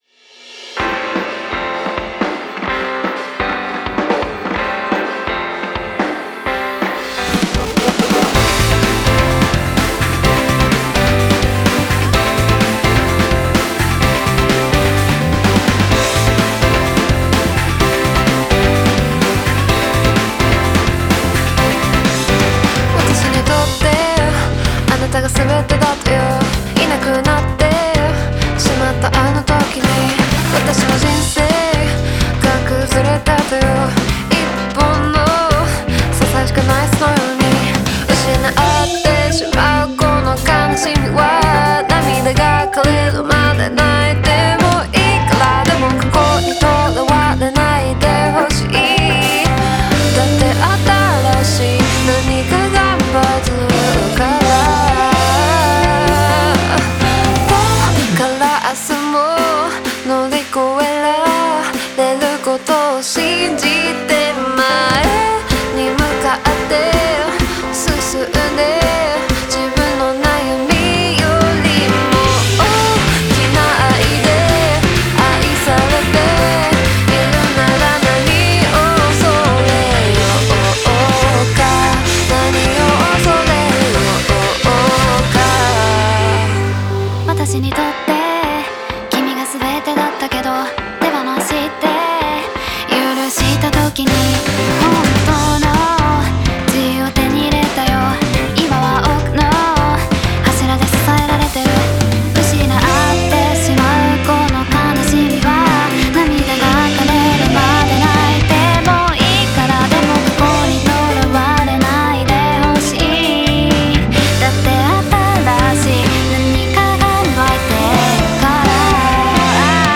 オリジナルKey：「C